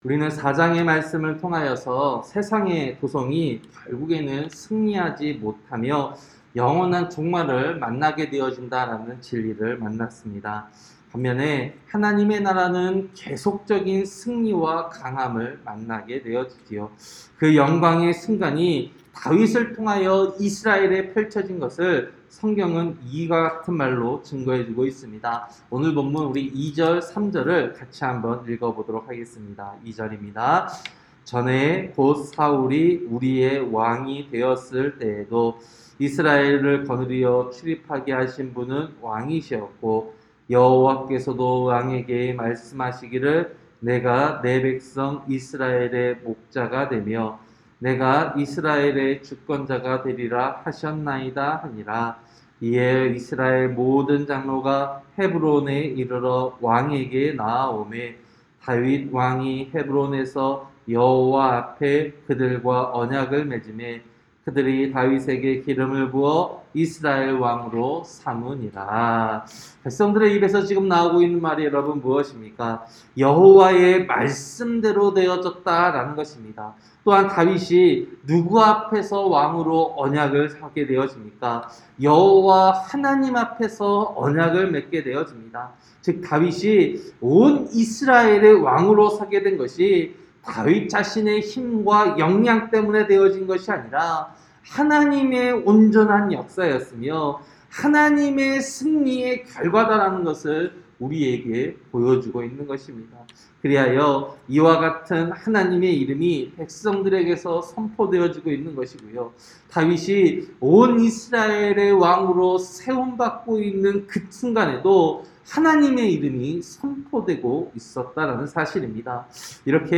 새벽설교-사무엘하 5장